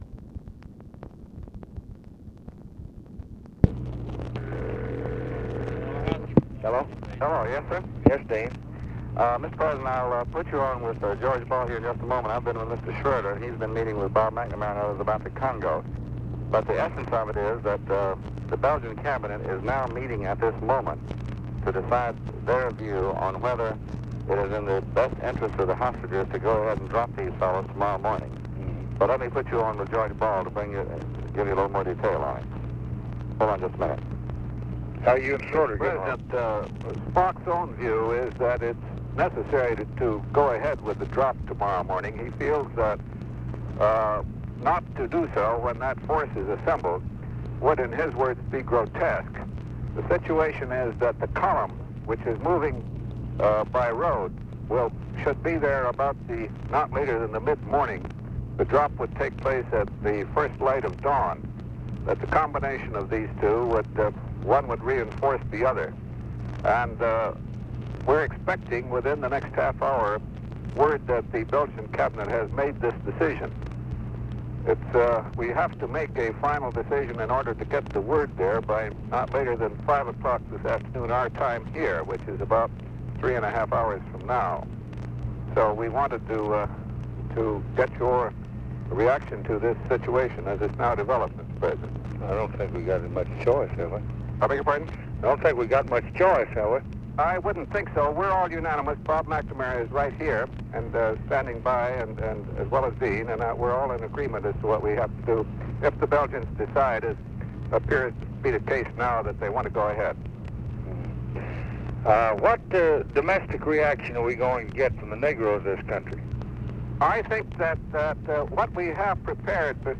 Conversation with DEAN RUSK, GEORGE BALL and ROBERT MCNAMARA, November 23, 1964
Secret White House Tapes